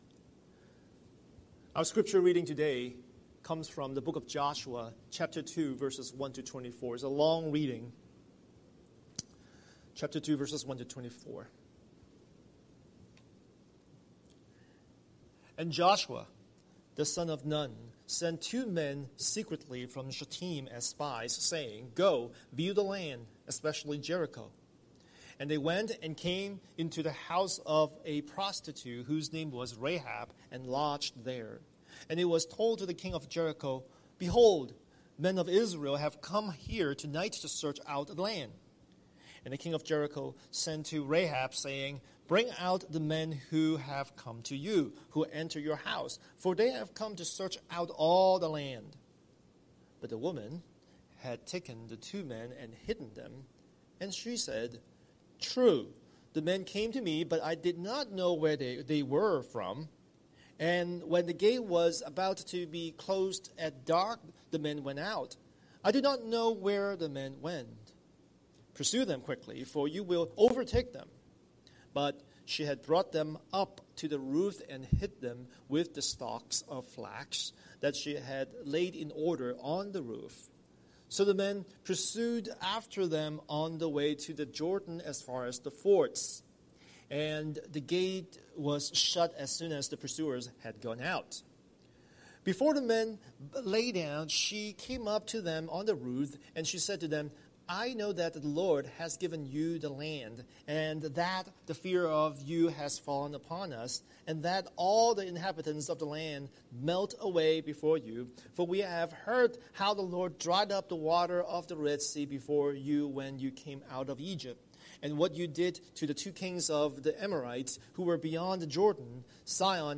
Scripture: Joshua 2:1–21 Series: Sunday Sermon